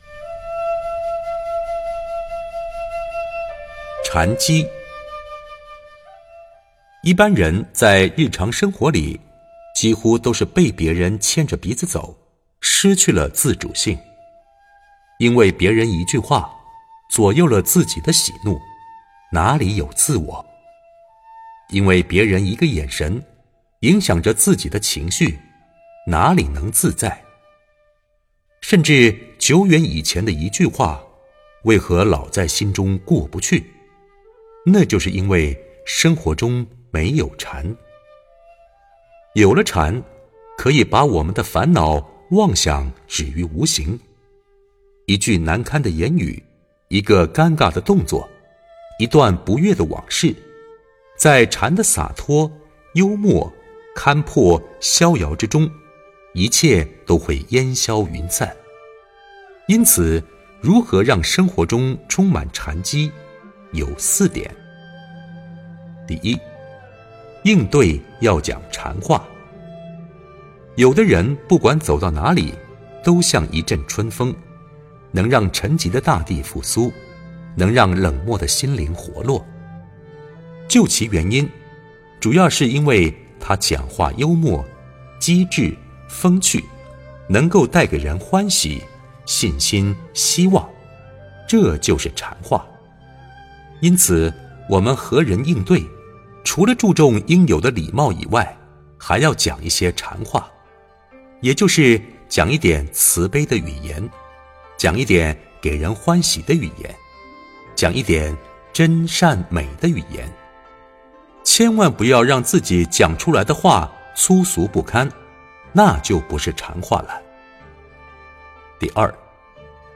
佛音 冥想 佛教音乐 返回列表 上一篇： 31.